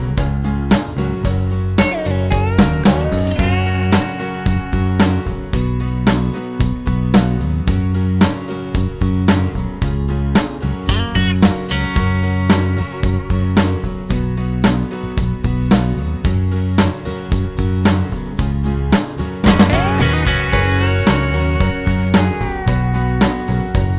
no Backing Vocals Country (Male) 2:56 Buy £1.50